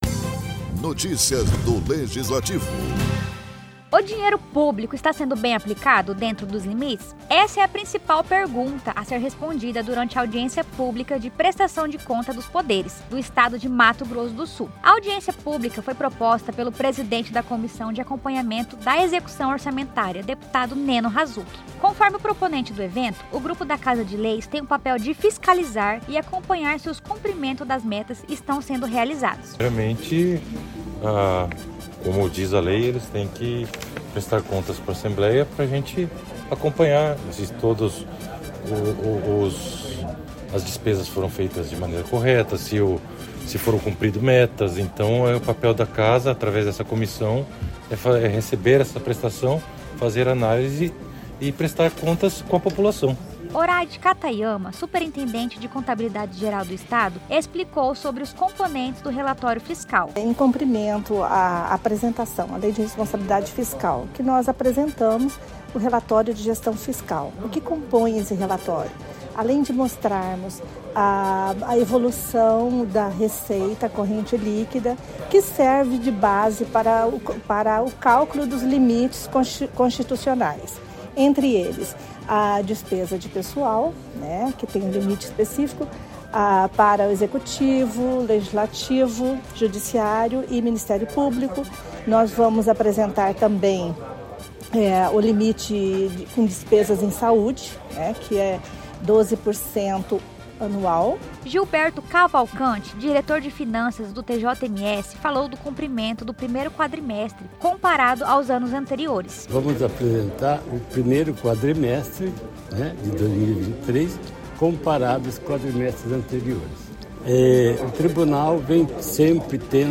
Assembleia Legislativa realizou  Audiência Pública para Prestação de Contas dos Poderes do Estado de Mato Grosso do Sul, no Plenarinho Deputado Nelito Câmara. O evento foi proposto pelo  deputado Neno Razuk (PL), presidente da Comissão de Acompanhamento da Execução Orçamentária.